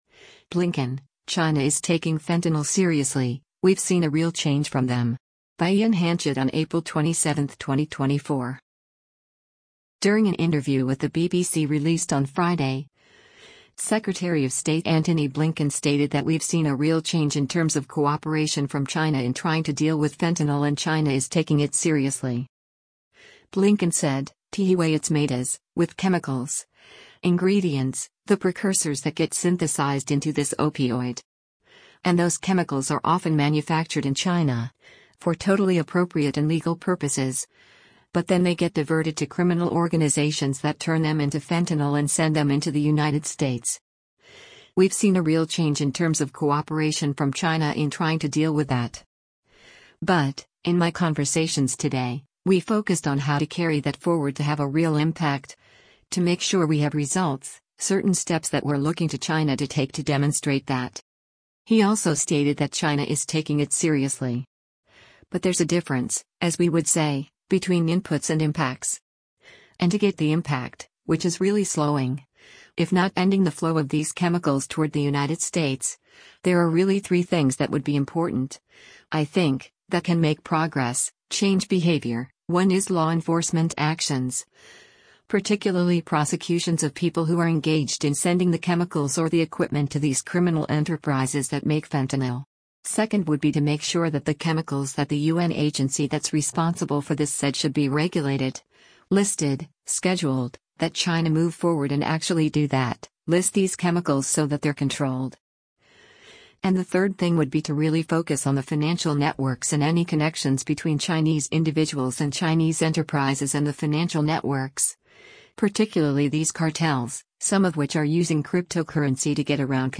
During an interview with the BBC released on Friday, Secretary of State Antony Blinken stated that “We’ve seen a real change in terms of cooperation from China in trying to deal with” fentanyl and China is “taking it seriously.”